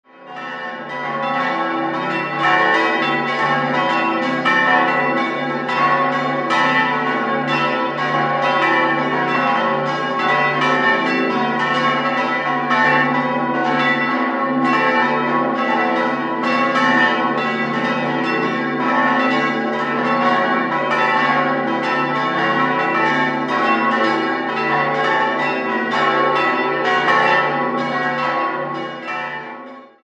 Im weitgehend gotischen, dreischiffigen Kirchenraum findet man heute eine frühbarocke Ausstattung vor. 6-stimmiges Geläute: cis'-fis'-a'-h'-cis''-e'' Die große Glocke wurde 1718 von Johann Adam Roth in Regensburg gegossen, die zweite und die vierte im Jahr 1951 von Rudolf Perner in Passau und die drei übrigen 1567 von Hans Stain in Amberg.